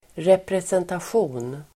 Uttal: [representasj'o:n]
representation.mp3